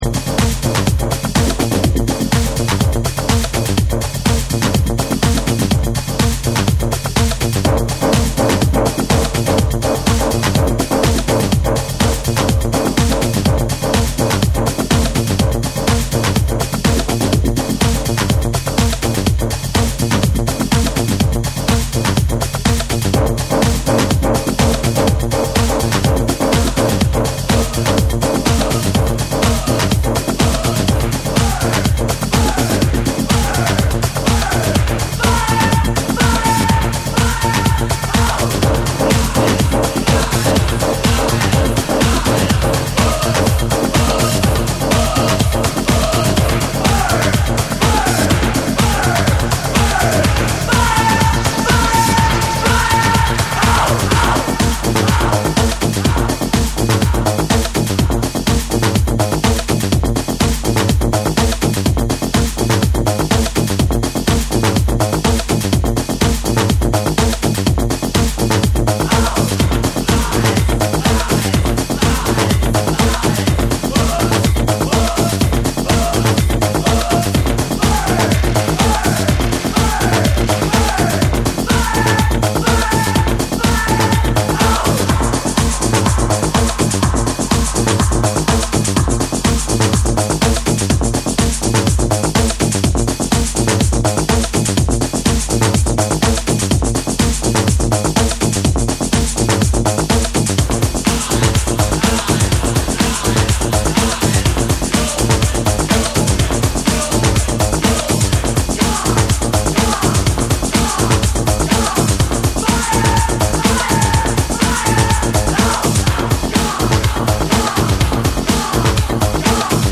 NEW WAVE & ROCK / RE-EDIT / MASH UP